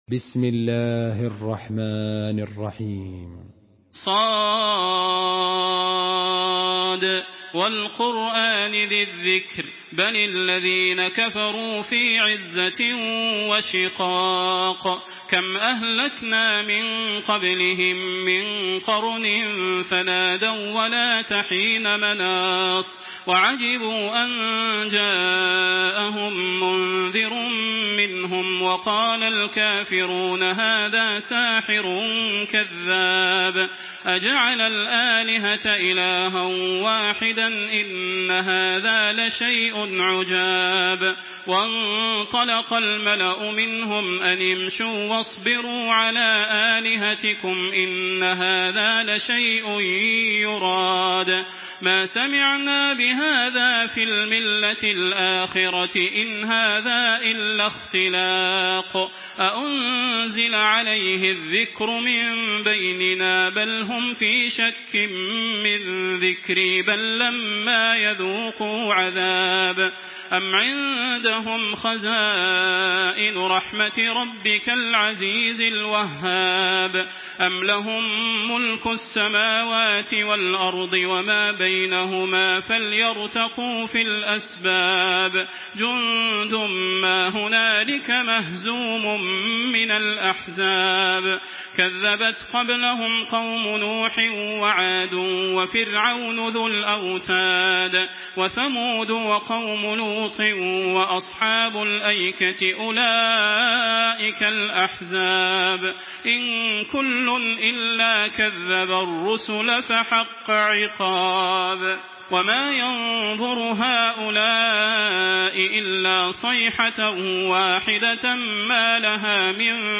تحميل سورة ص بصوت تراويح الحرم المكي 1426
مرتل